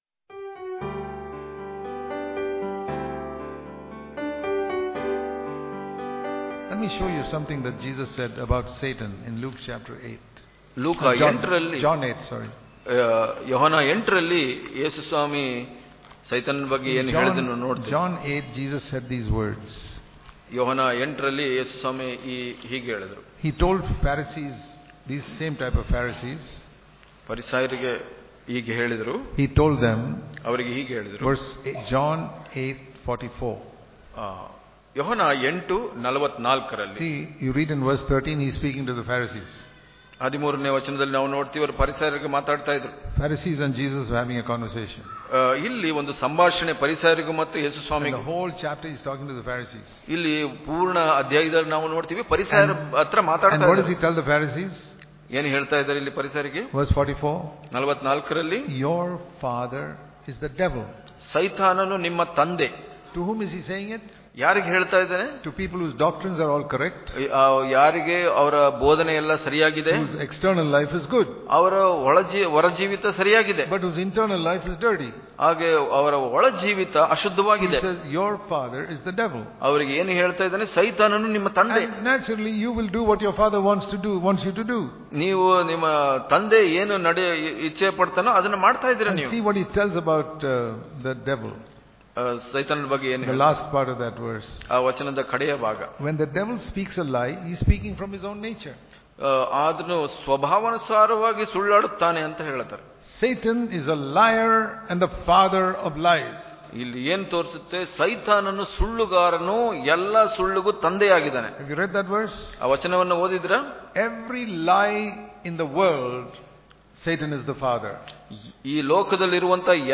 March 22 | Kannada Daily Devotion | We Must Believe The Word Of God, Not The Lies Of Satan Daily Devotions